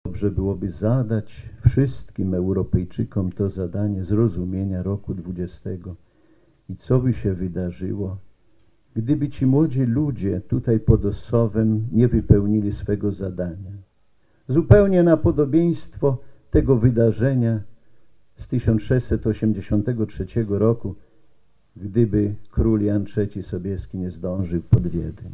W Ossowie w kościele Matki Bożej przy Cmentarzu Poległych biskup warszawsko-praski Romuald Kamiński odprawił mszę świętą w 98. rocznicę Bitwy Warszawskiej.